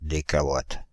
Ääntäminen
France (Île-de-France): IPA: /de.ka.wat/